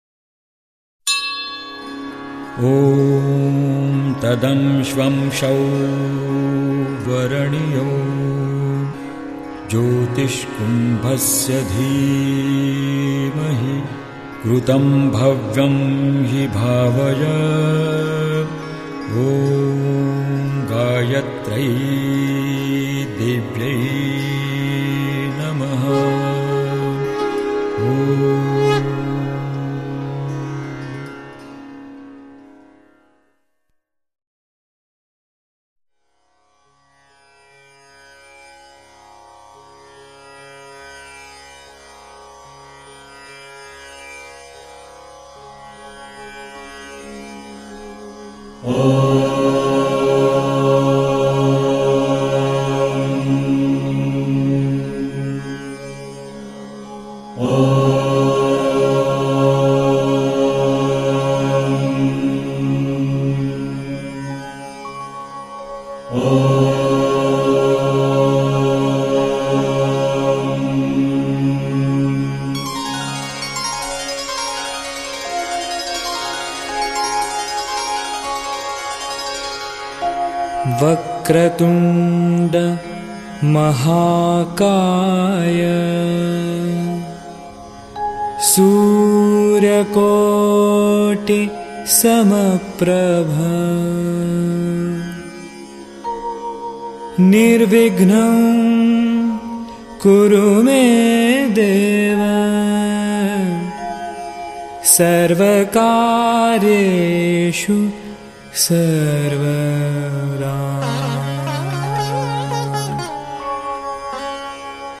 Devotional Song